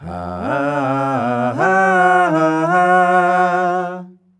HAAAAAAH.wav